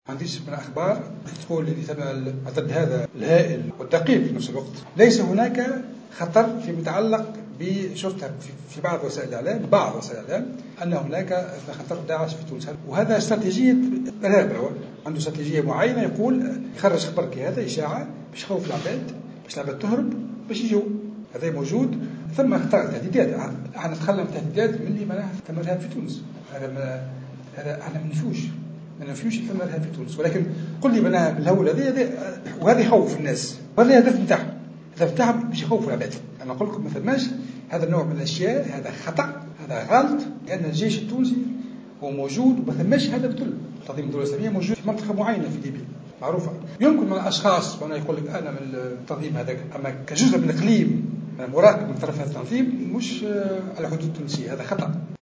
وأضاف الوزير على هامش القمة الاستثنائية لوزراء الدفاع لدول المُبادرة خمسة زائد خمسة أن هذه الأخبار تدخل في إطار استراتيجية المجموعات الإرهابية التي تهدف إلى الإرباك والتخويف،مؤكدا أن الجيش التونسي موجود ويسهر على حماية الحدود.